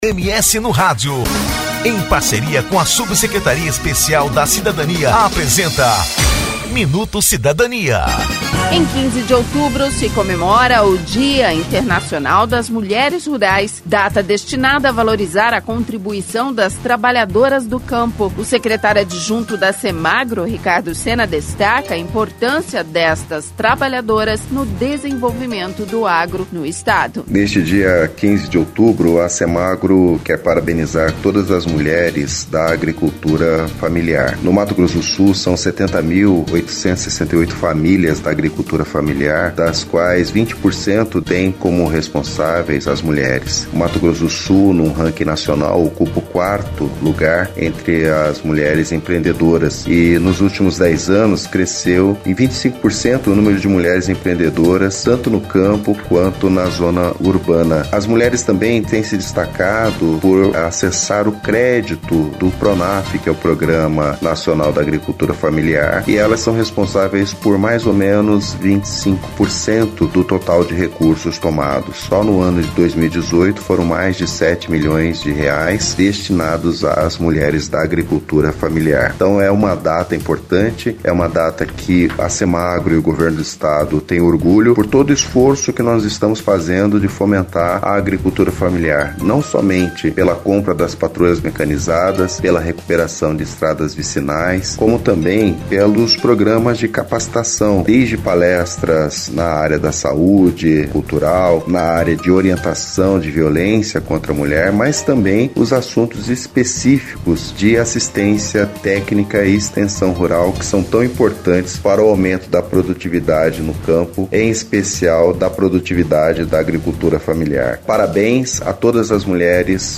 O secretário adjunto da Semagro, Ricardo Sena, destaca a importância dessas trabalhadoras no desenvolvimento do Estado.